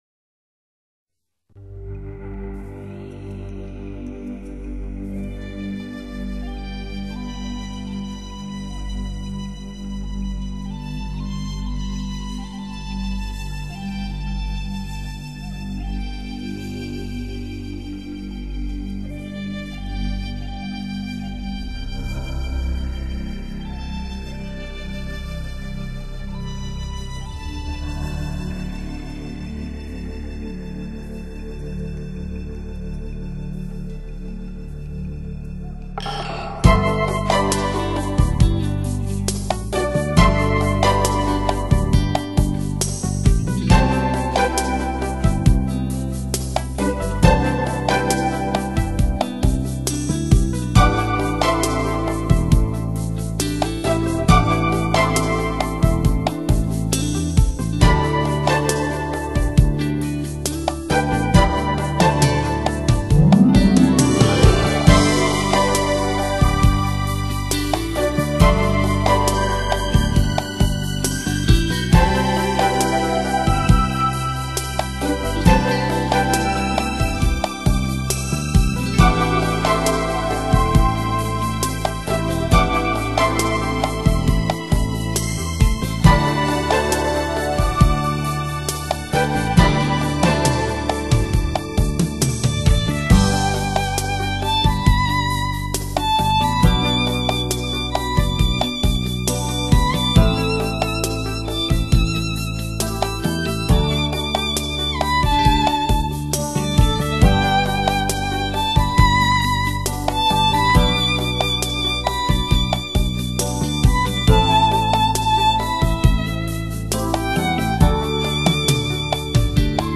这是一张具有治疗功效的减压音乐专辑
你的神经将得到最大限度的放松